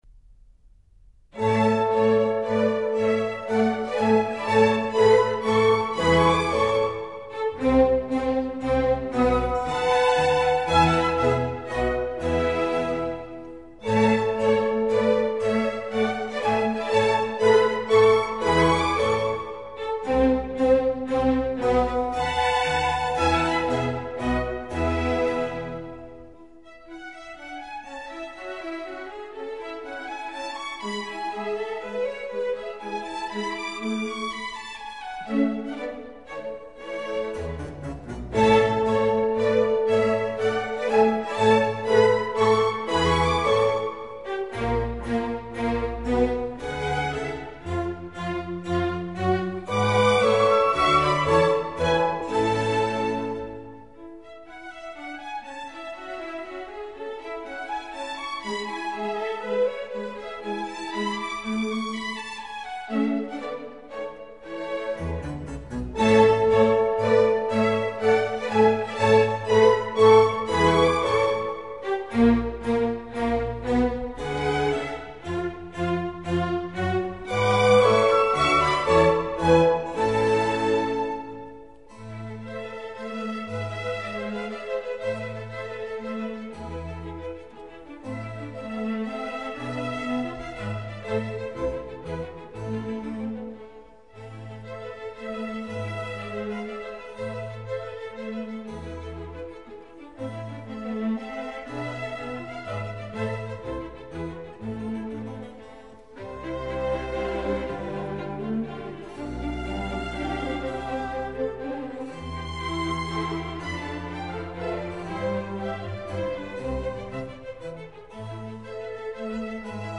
Menuetto